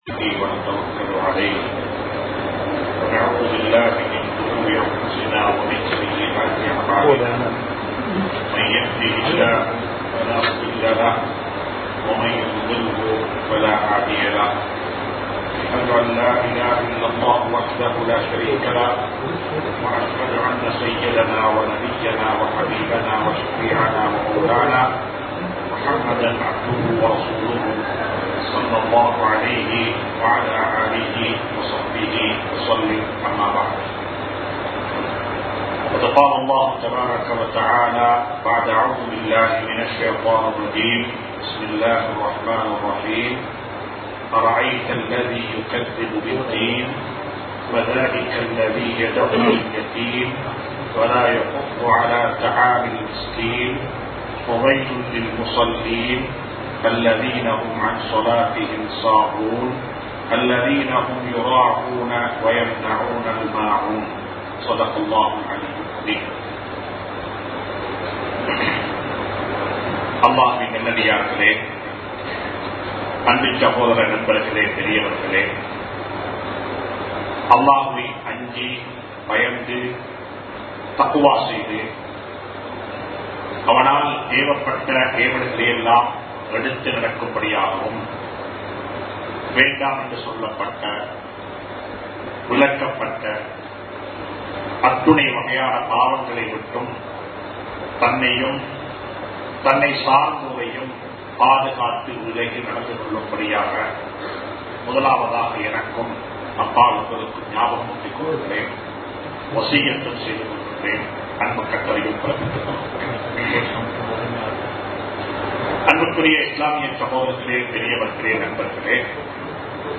மற்றவர்களுக்கு உதவி செய்யுங்கள் | Audio Bayans | All Ceylon Muslim Youth Community | Addalaichenai